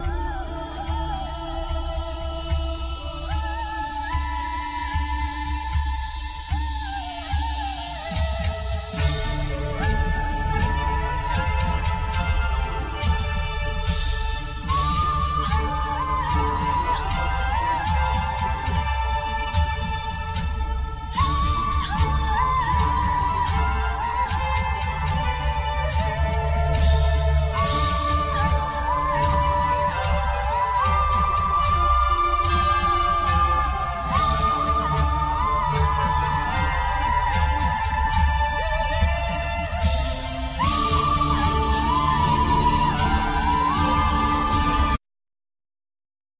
Keyboards
Voice
Drum programming
Guitar
Bass
Piano
Violin